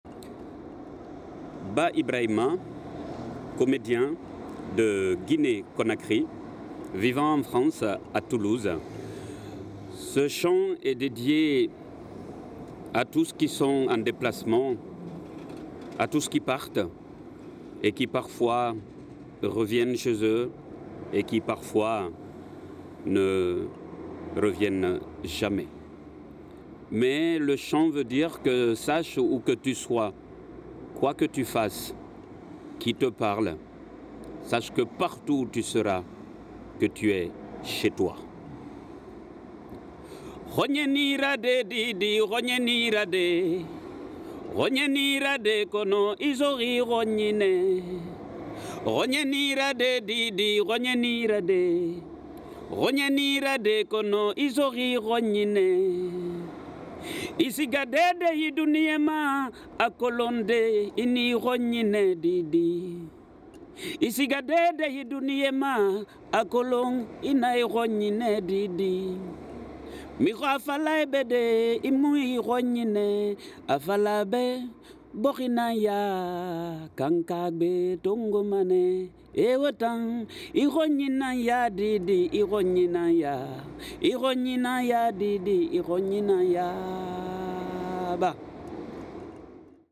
chant de Guinée Conakry en Pèlè et en Soussou